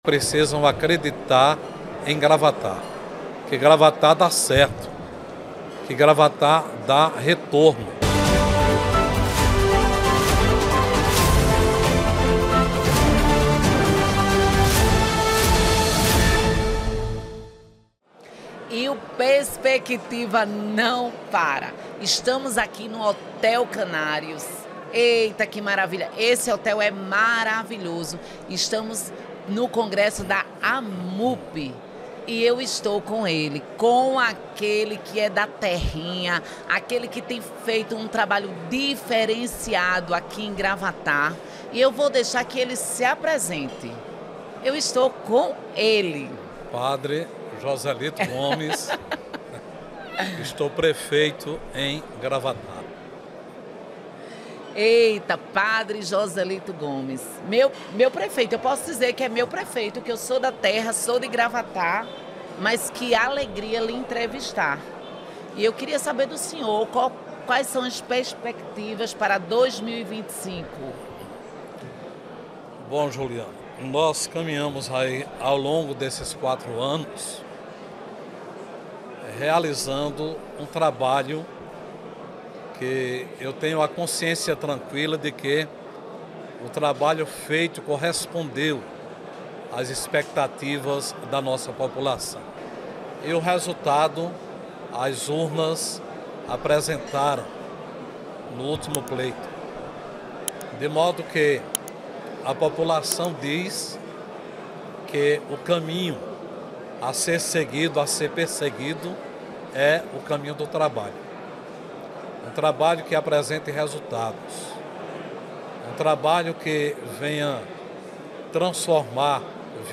Em entrevista ao Podcast Perspectiva, da Rede Você, Padre Joselito também ressaltou a gratidão do povo que reconheceu o seu trabalho. Ele também falou sobre a importância da gestão responsável dos recursos públicos como um pilar essencial para garantir o desenvolvimento sustentável do município. Ele destacou seu compromisso em avançar com projetos que impactam positivamente a vida de todos os moradores, tanto da sede quanto das comunidades rurais, buscando sempre a melhoria para a vida das pessoas.